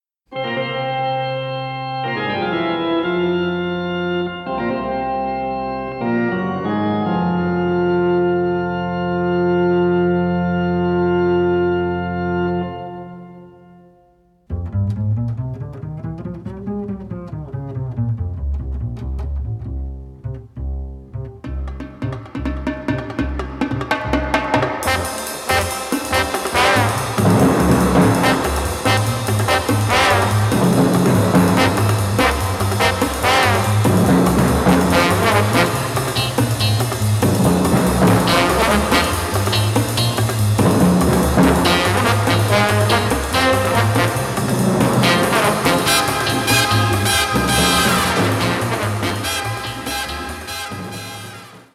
Originalmusik aus den deutschen Kult-Krimis